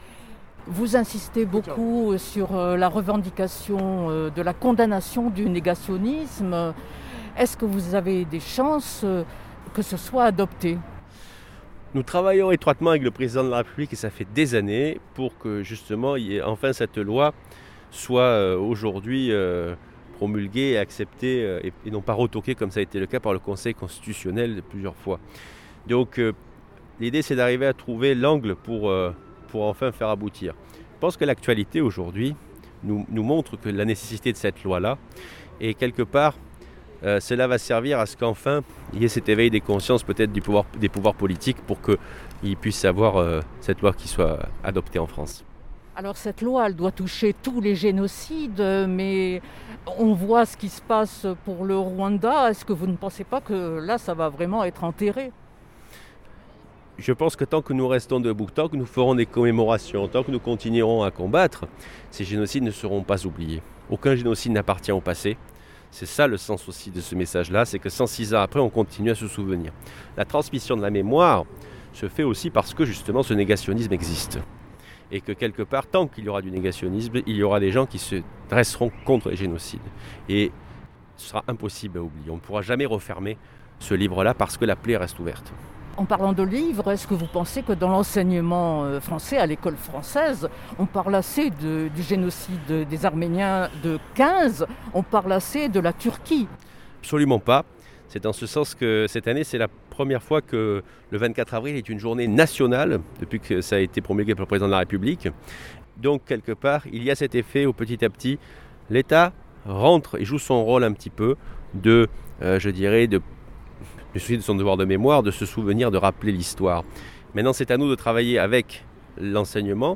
Entretiens